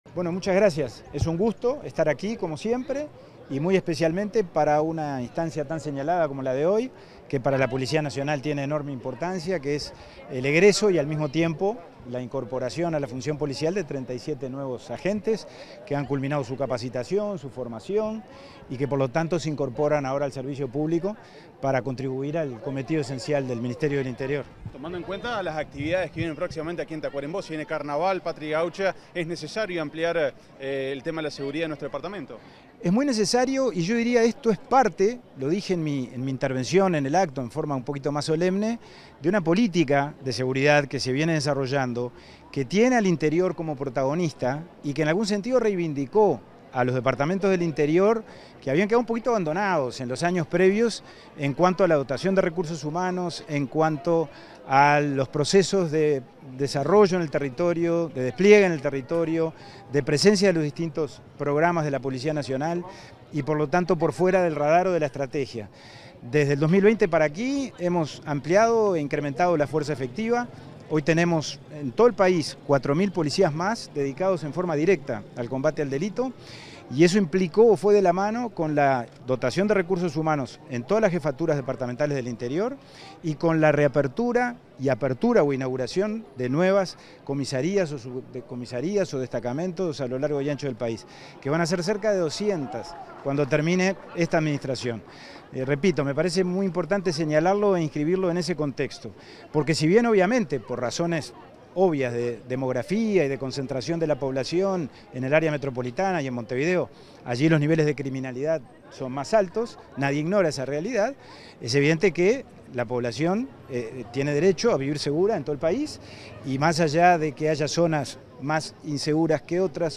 Declaraciones del ministro interino del Interior, Pablo Abdala
Declaraciones del ministro interino del Interior, Pablo Abdala 31/01/2025 Compartir Facebook X Copiar enlace WhatsApp LinkedIn Tras participar en la ceremonia de egreso de nuevos agentes policiales en Tacuarembó, este 31 de enero, el ministro interino del Interior, Pablo Abdala, dialogó con los medios informativos presentes.